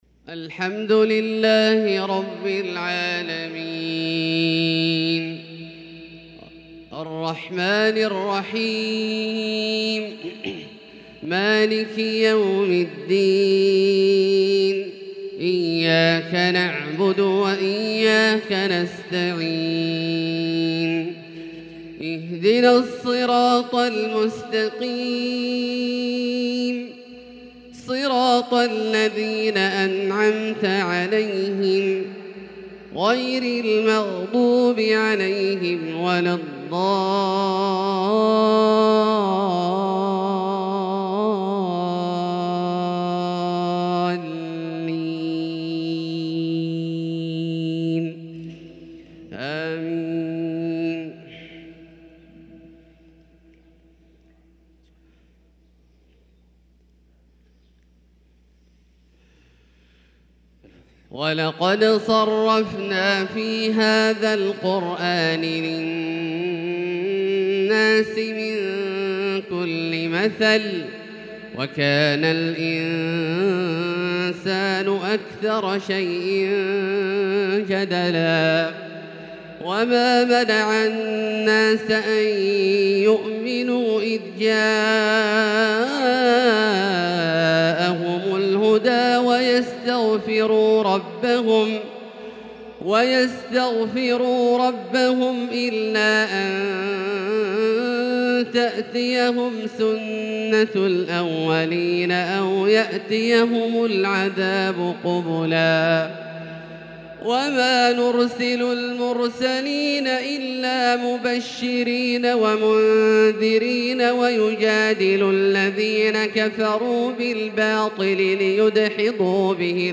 فجر الثلاثاء 7-7-1443هـ من سورة الكهف | Fajr prayer from surat Al-Kahf 8-2-2022 > 1443 🕋 > الفروض - تلاوات الحرمين